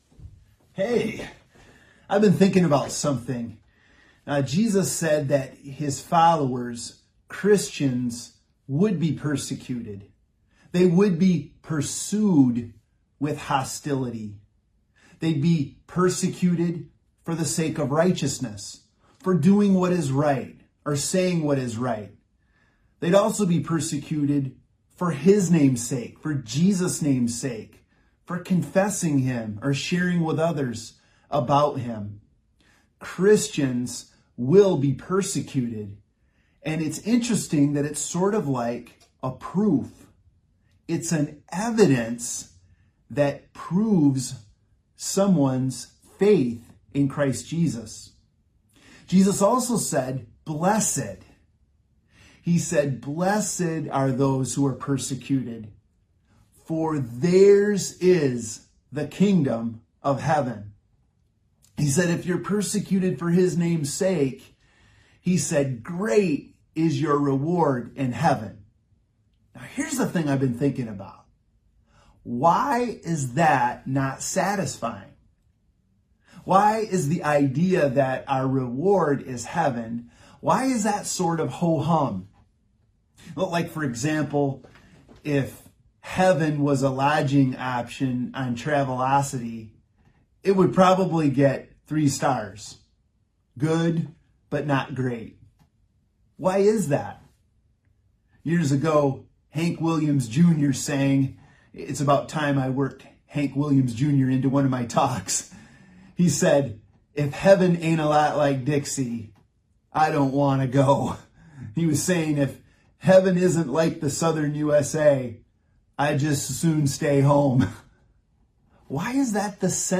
Service Type: Sunday Morning Preacher